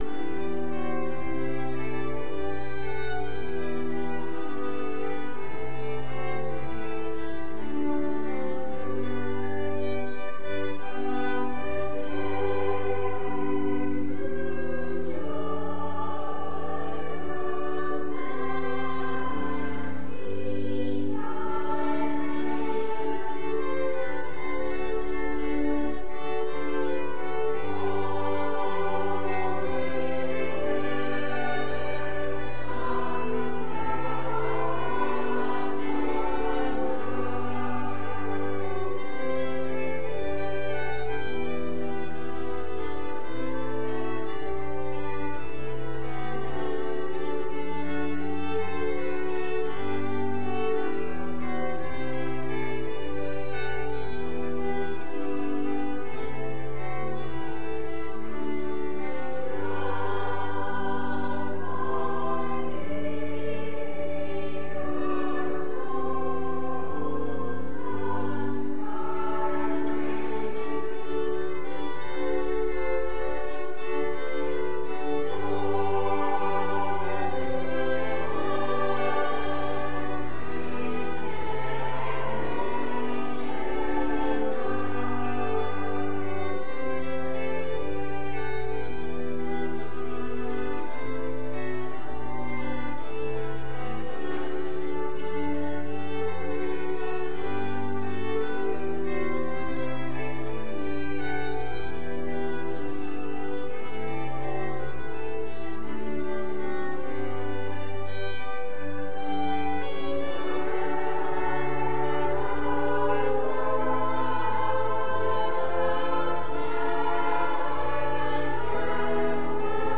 LCOS Worship Service